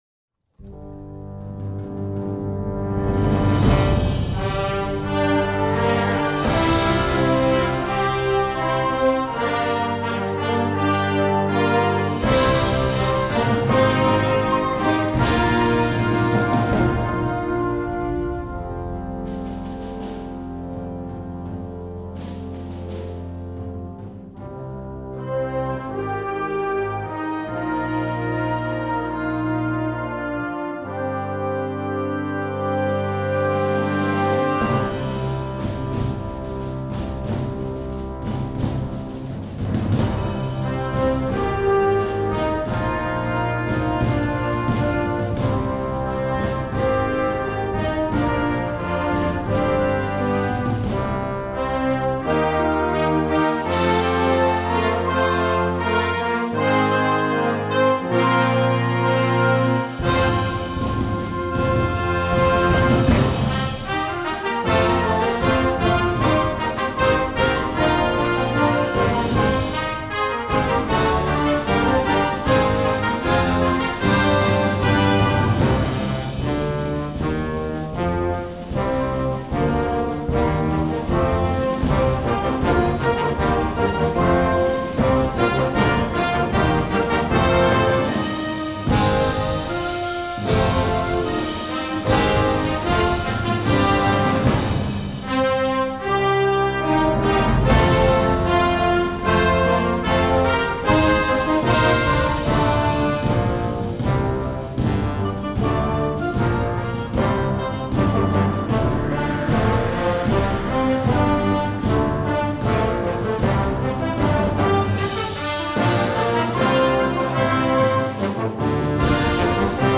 Gattung: Unterhaltungsmusik für flexibles Jugendblasorches.
Besetzung: Blasorchester
mit einem täuschend wuchtigen und ausgefeilten Sound